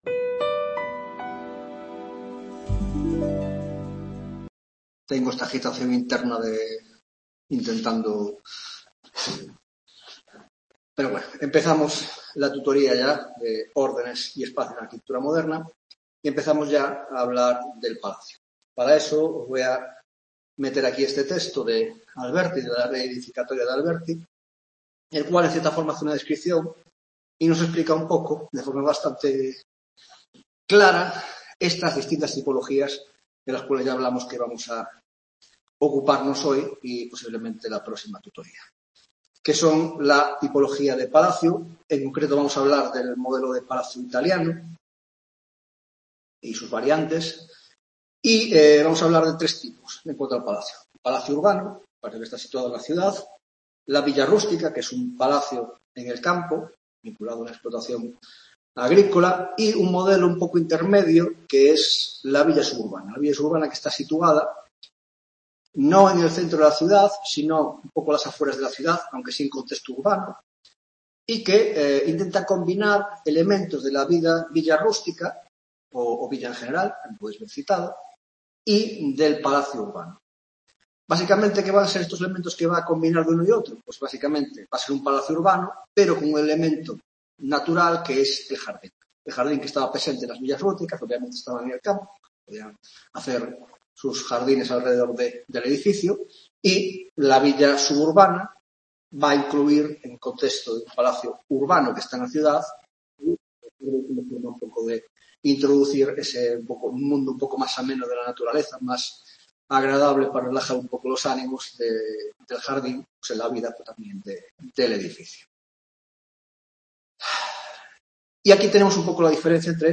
8ª Tutoria de Órdenes y Espacio en la Arquitectura Moderna (grado de Historia del Arte): Tipologías: El Palacio Urbano: 1) Introducción: 1.1) Palacio Urbano, Villa Rústica y Villa Suburbana: 1.2) Origen y contexto de la aparición del Palazzo; 2) El Palazzo y el modelo florentino; 3) Variantes y evolución del modelo florentino; 4) El Modelo Veneciano de Palazzo; 5) El Palacio Barroco y la apertura de la arquitectura palacial al entorno urbano